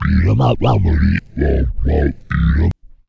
Track 11 - Vocal Scratched.wav